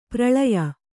♪ praḷaya